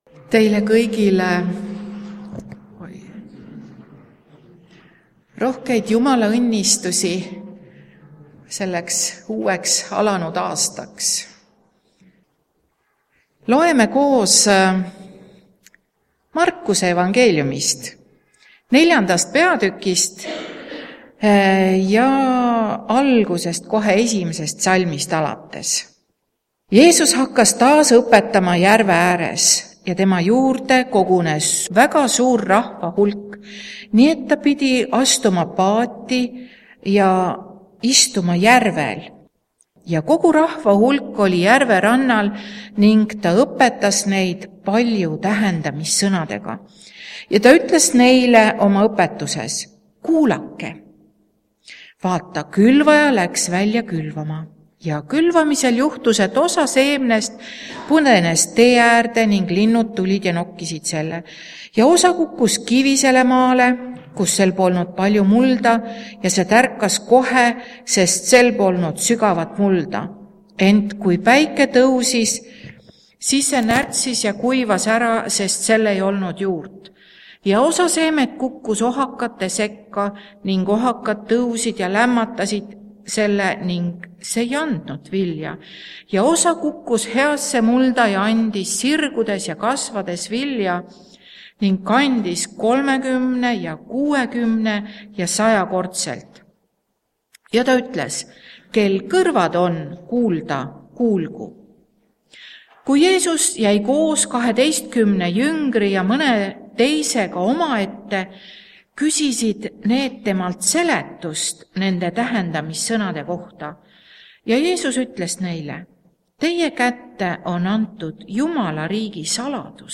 Jutlused